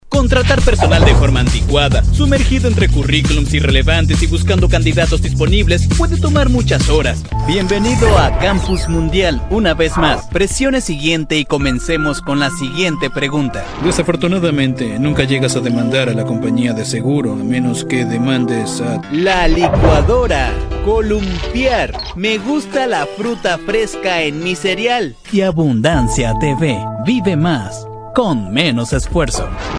男西101T 西班牙语男声 全能 低沉|激情激昂|大气浑厚磁性|沉稳|娓娓道来|科技感|积极向上|时尚活力|神秘性感|调性走心|感人煽情|素人|脱口秀